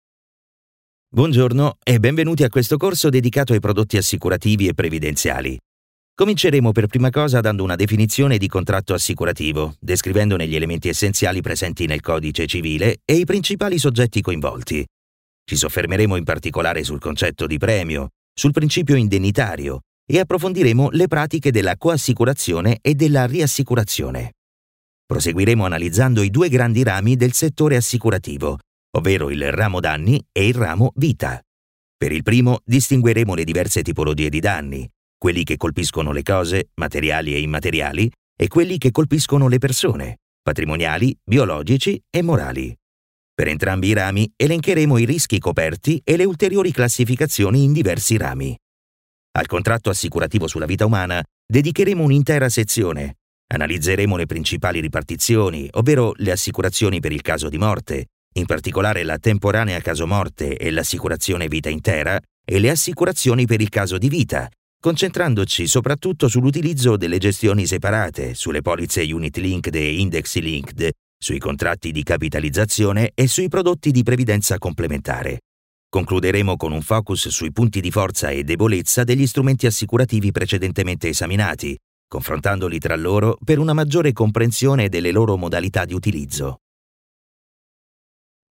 and i'm a Professional Italian Voiceover with baritonal and adaptable voice for each project
Sprechprobe: eLearning (Muttersprache):
I have a Home Studio and all the knowledge to edit and send professional, quality audio.